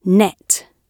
net-gb.mp3